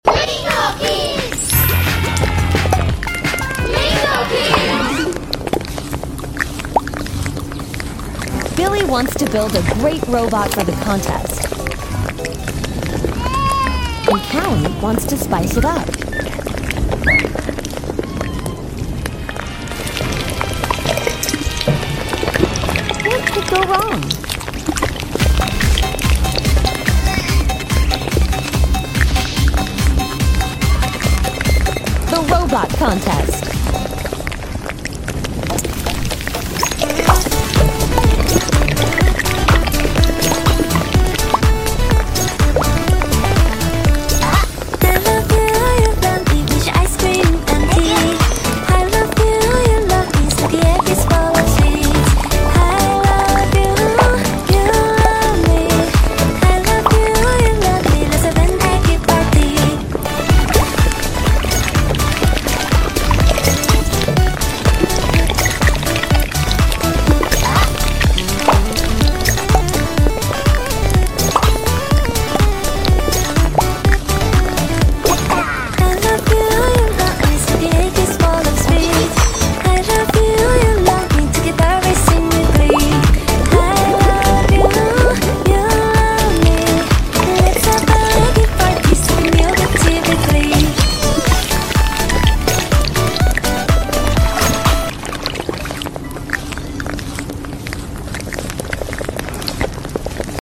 Roblox eating with blob emoji sound effects free download